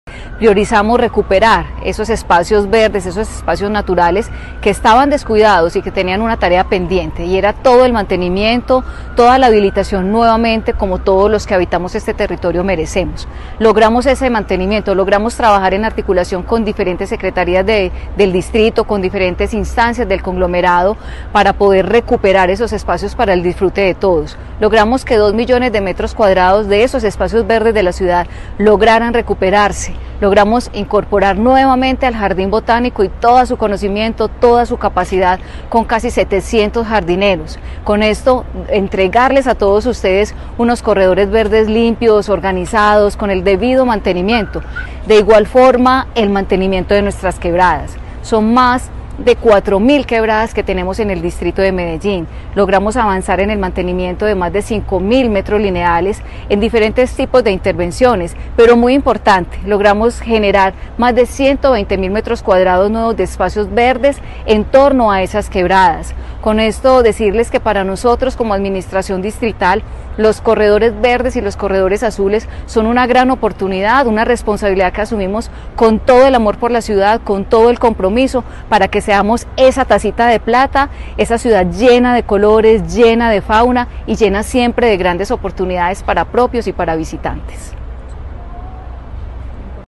Palabras de Ana Ligia Mora, secretaria de Medio Ambiente La Alcaldía de Medellín destinó $82.000 millones para fortalecer una ciudad más verde y mejorar la calidad de vida de sus habitantes.